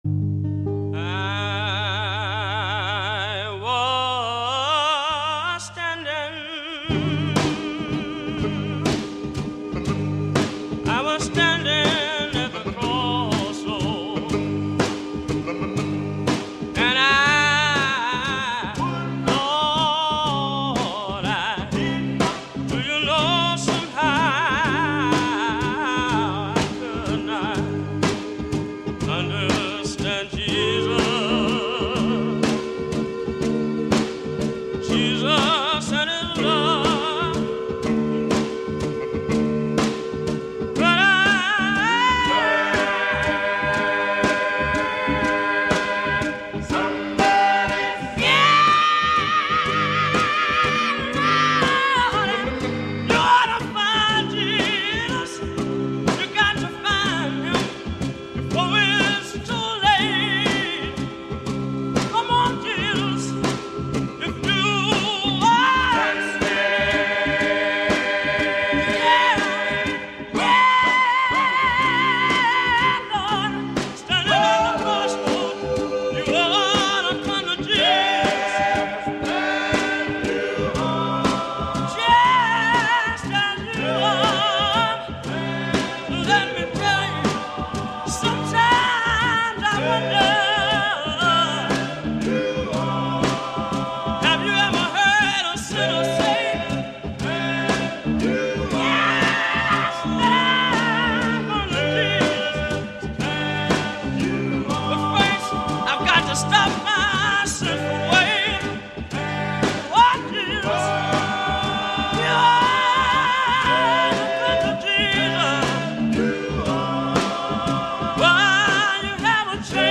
Anonymous Bluegrass Garage Group
Anonymous Clawhammer Banjer Player
New Book Gospel Shape Note Singing